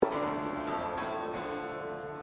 The “haunted” theme comes closer and closer until this statement explodes in the bass: